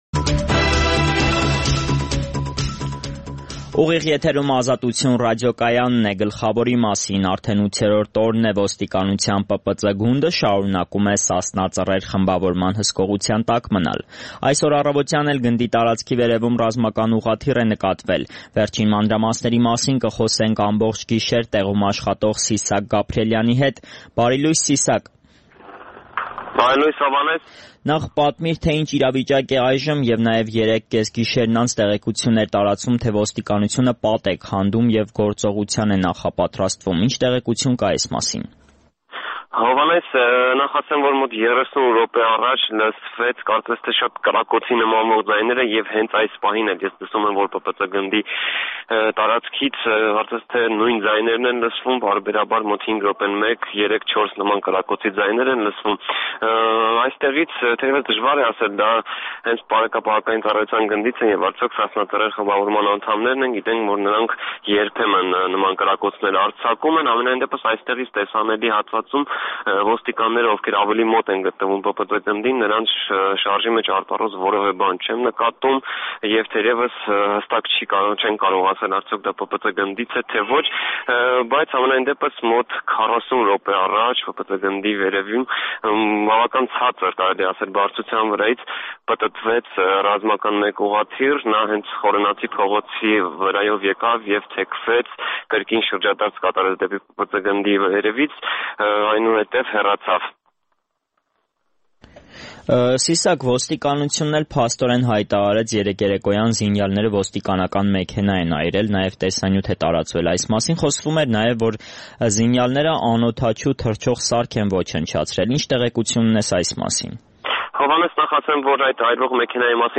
Ուղղաթիռ ՊՊԾ գնդի տարածքի վրա, կրակոցների նմանվող ձայներ